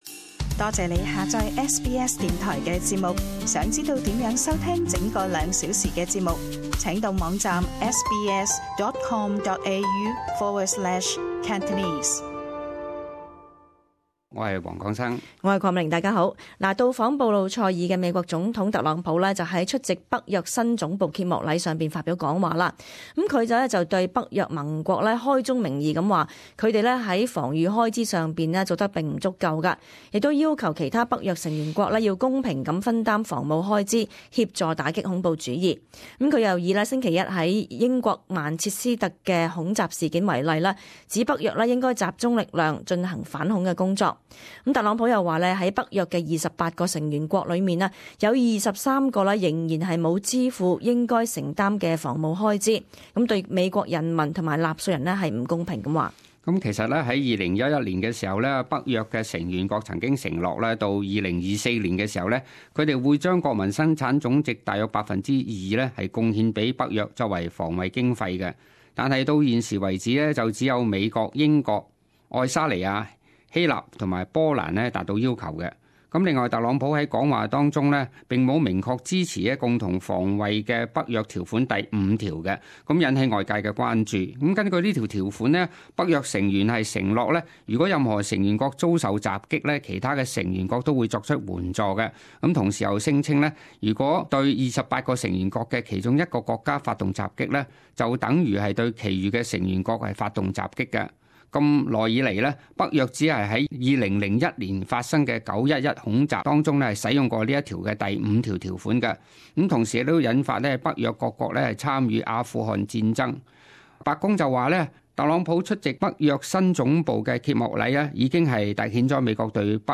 [時事報導] 特朗普:北約國需分擔防務開支打擊恐怖主義